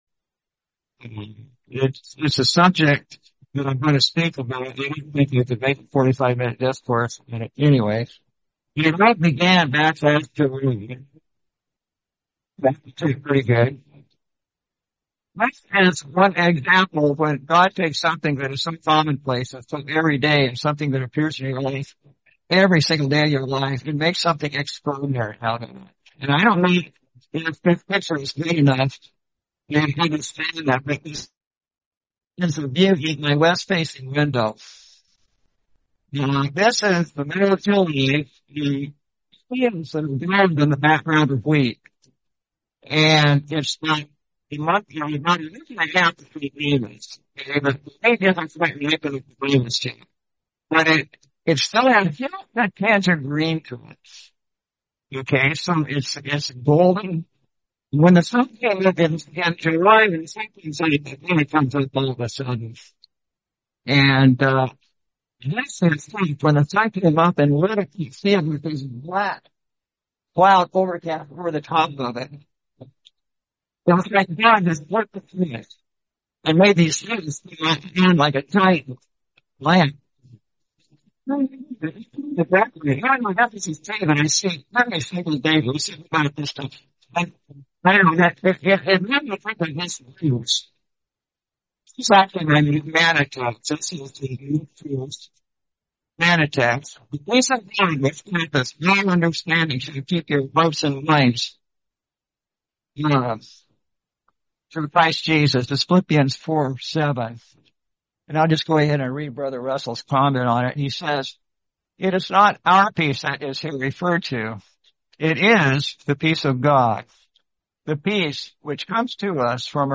Series: 2026 Sacramento Convention